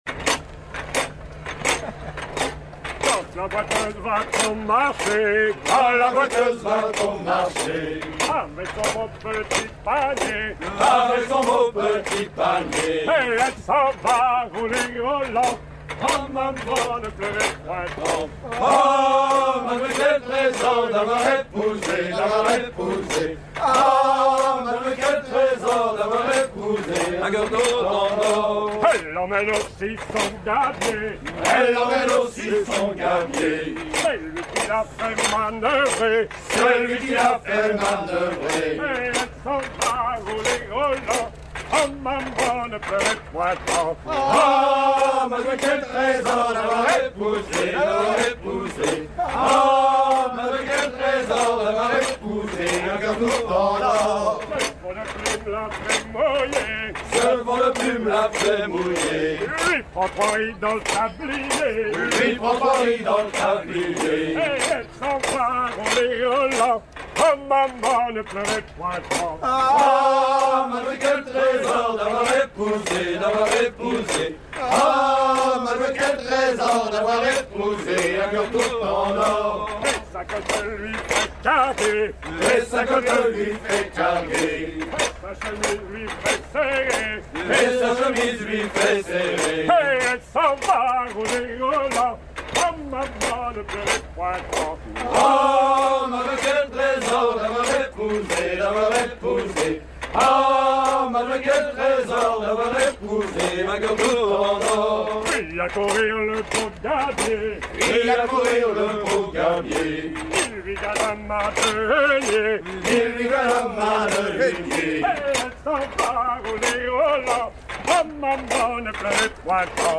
gestuel : à virer au cabestan
circonstance : maritimes
Pièce musicale éditée